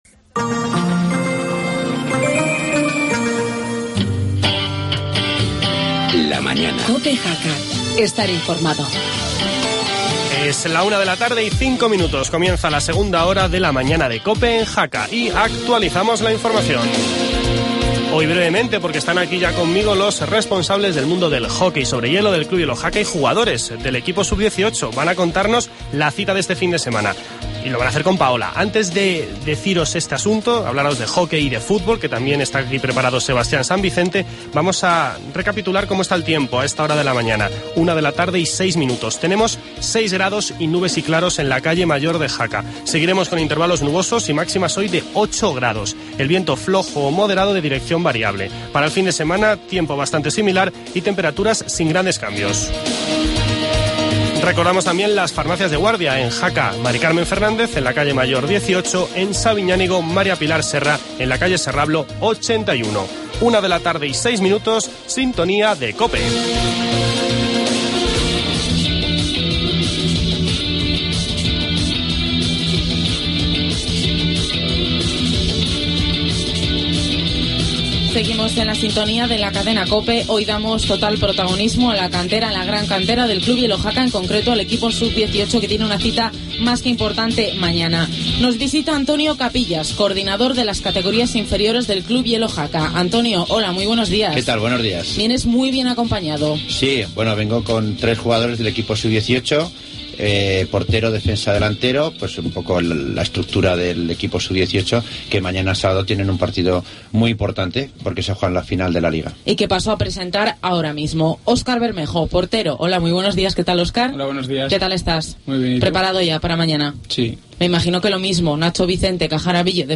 charla con el equipo sub 18 de hockey sobre hielo y con el Jacetano.